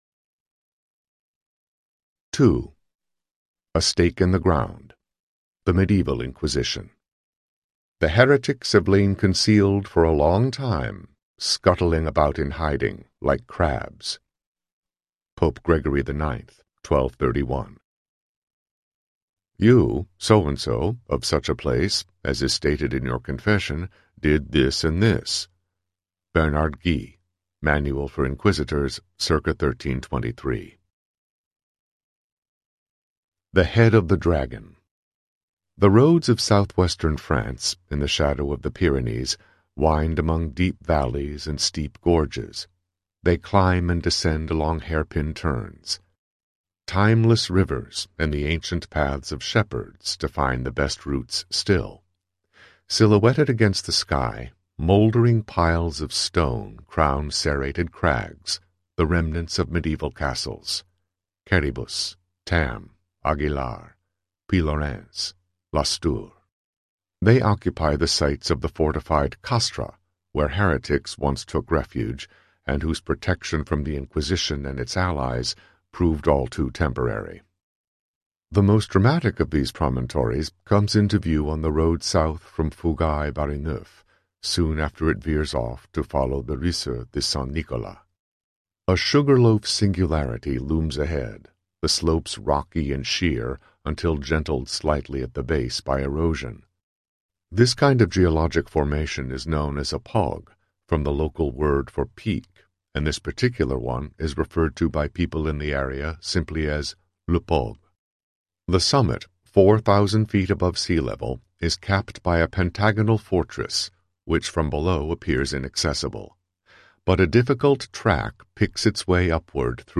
God’s Jury Audiobook
Narrator
– Unabridged